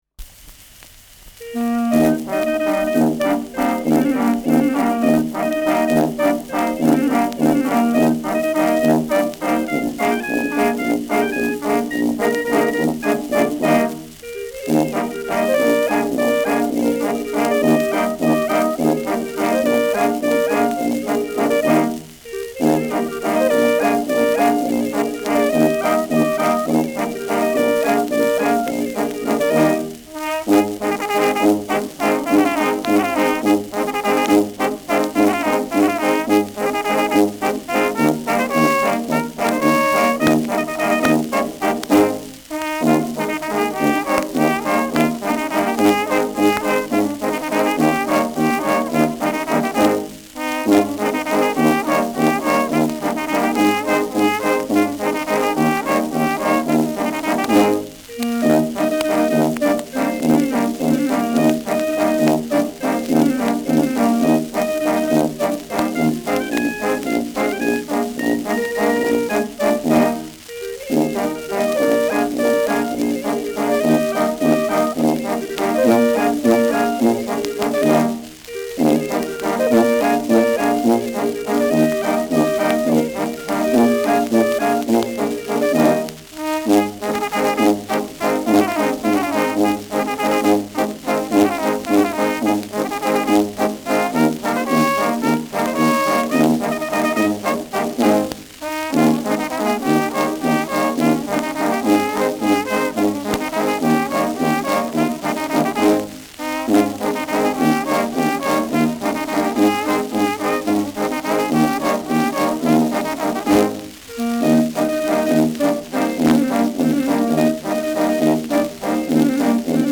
Schellackplatte
leichtes Rauschen : leichtes Knistern : gelegentliches Knacken
Kapelle Jais (Interpretation)
[München] (Aufnahmeort)